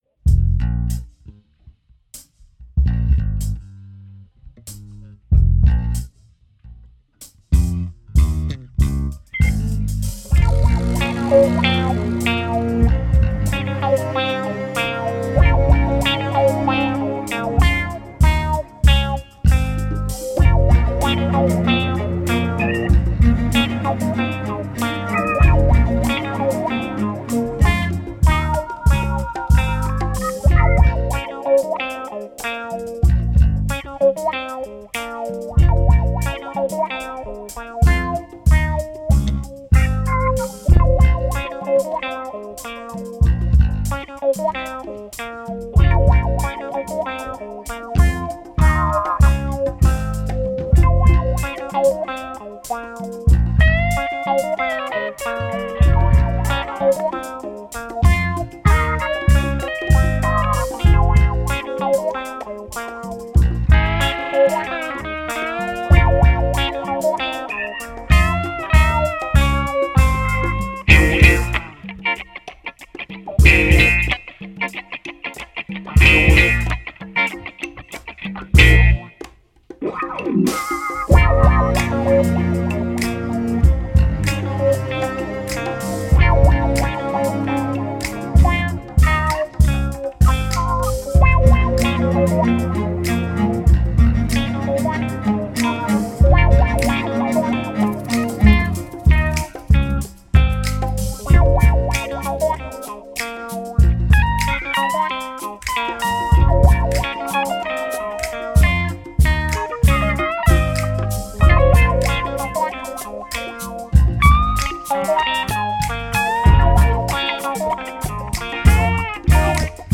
Genre: Funk.